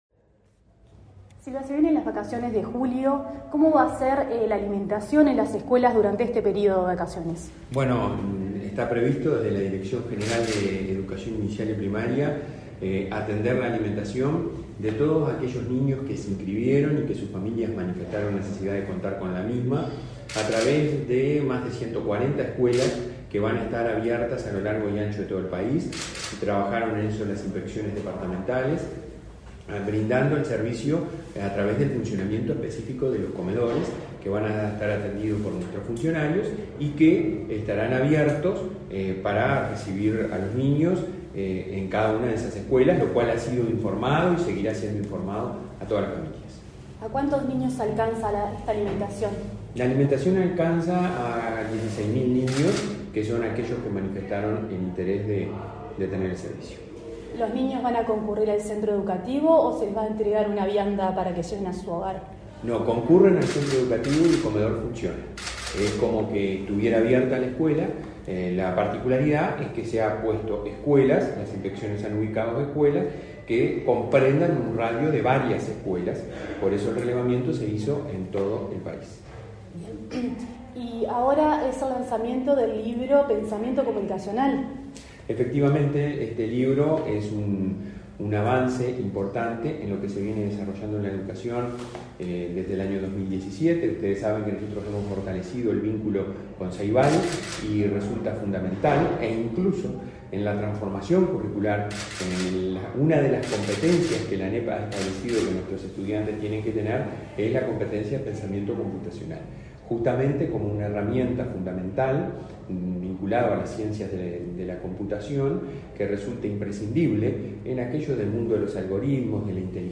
Entrevista al presidente del Consejo Directivo Central de la ANEP, Robert Silva
Entrevista al presidente del Consejo Directivo Central de la ANEP, Robert Silva 08/07/2022 Compartir Facebook X Copiar enlace WhatsApp LinkedIn En el marco de la presentación de un libro sobre pensamiento computacional, de la Administración Nacional de Educación Pública (ANEP) y Ceibal, este 8 de julio, Silva informó a Comunicación Presidencial de las acciones en referencia a la alimentación escolar durante las vacaciones de invierno.